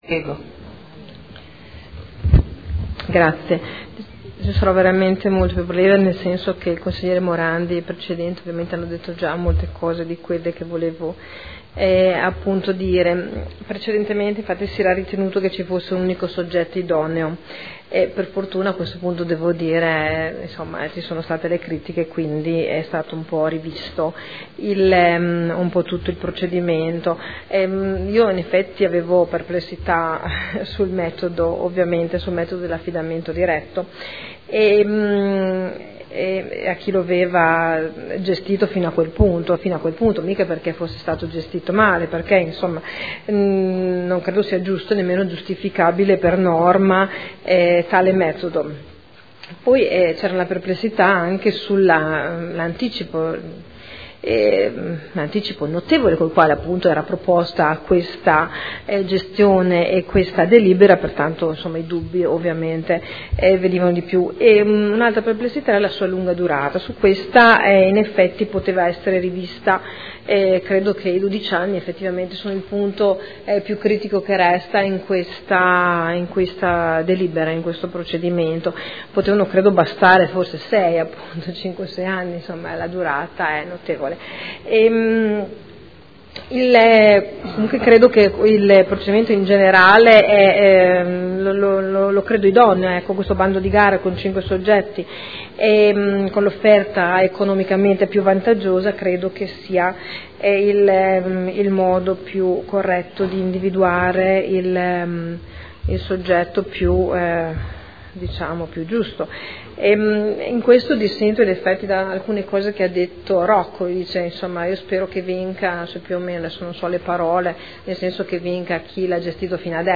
Sandra Poppi — Sito Audio Consiglio Comunale
Seduta del 16 gennaio. Proposta di deliberazione: Concessione gestione piscina Dogali: indirizzi. Dibattito